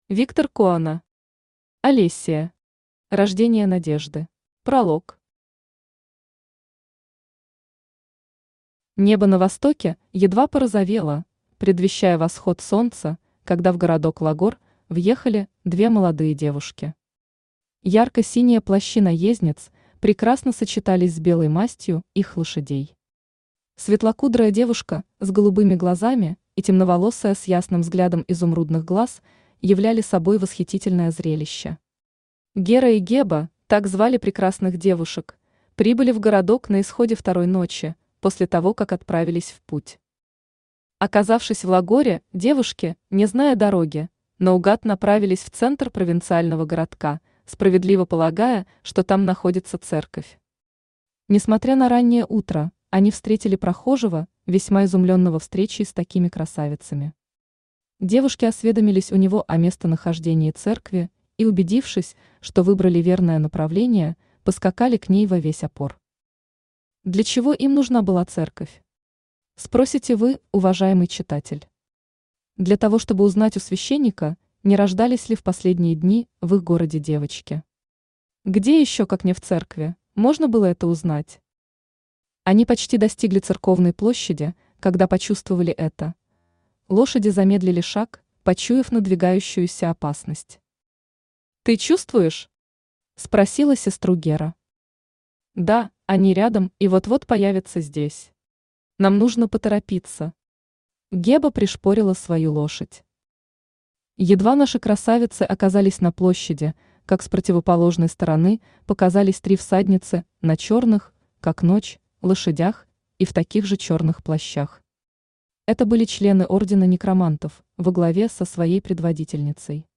Аудиокнига Алессия. Рождение надежды.
Автор Виктор Коона Читает аудиокнигу Авточтец ЛитРес.